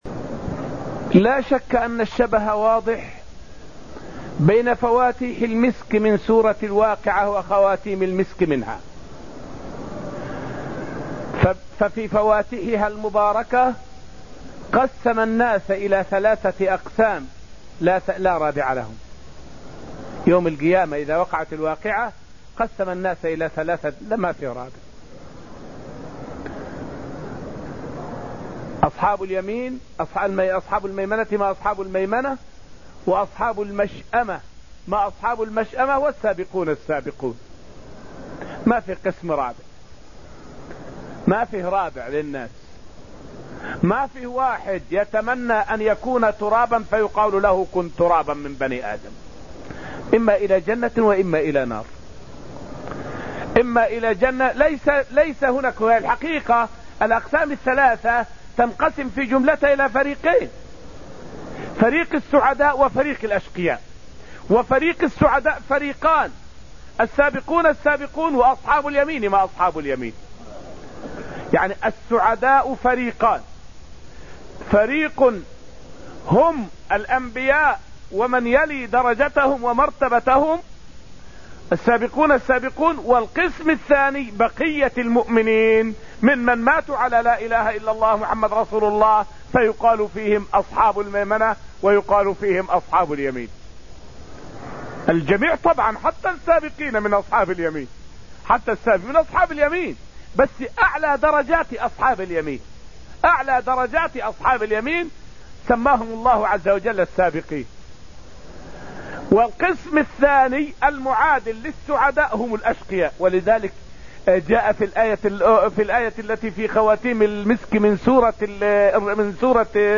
فائدة من الدرس التاسع من دروس تفسير سورة الواقعة والتي ألقيت في المسجد النبوي الشريف حول مناسبة آخر سورة الواقعة لأولها.